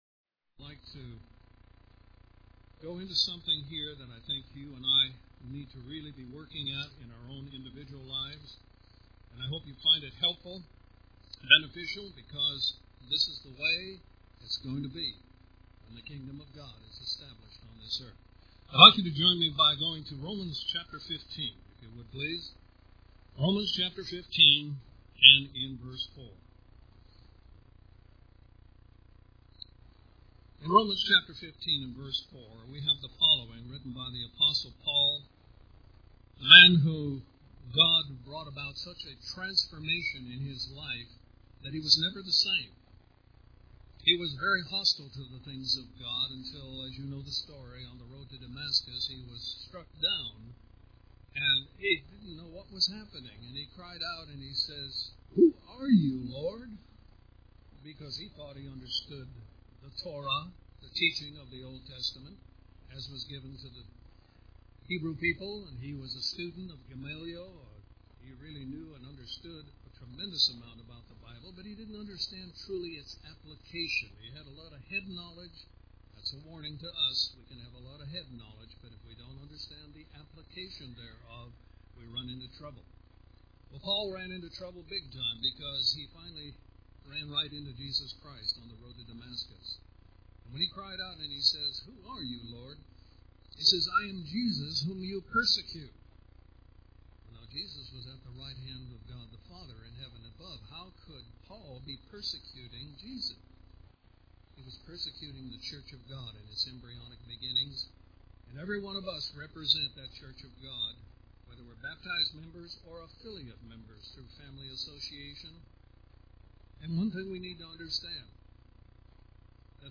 This sermon was given at the Panama City Beach, Florida 2012 Feast site.